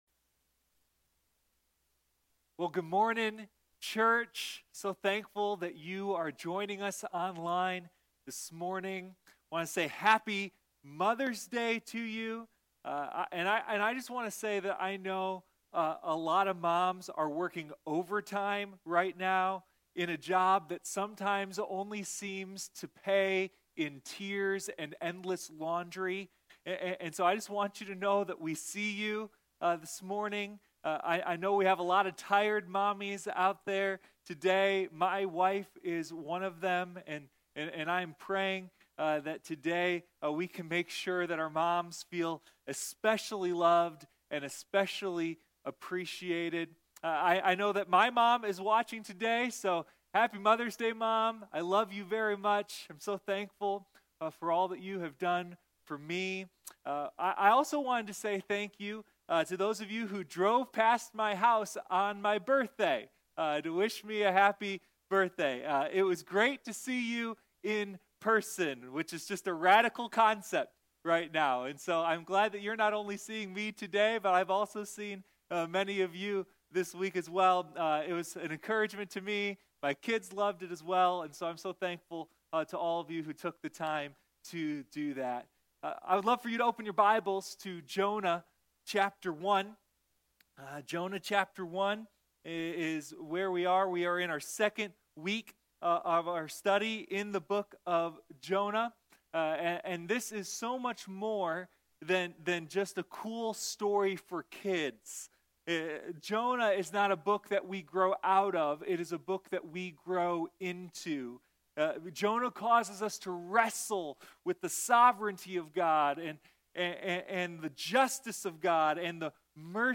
Sunday Morning Jonah: a deep dive into God's mercy